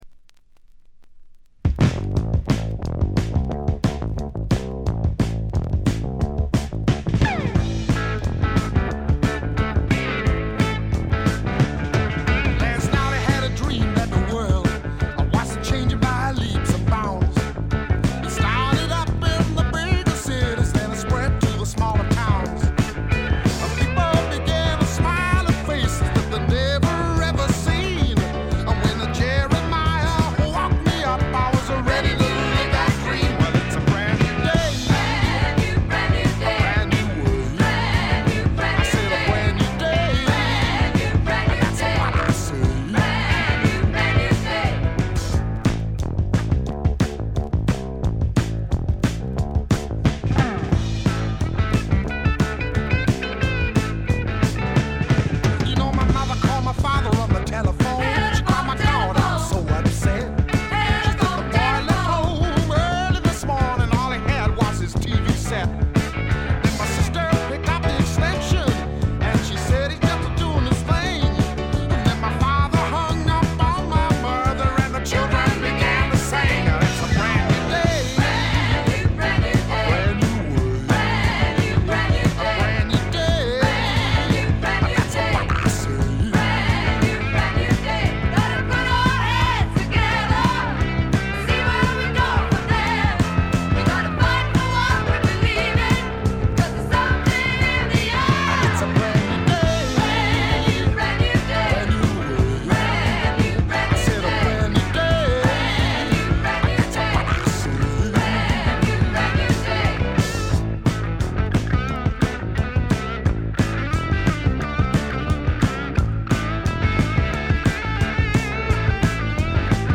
チリプチがちらほら。
試聴曲は現品からの取り込み音源です。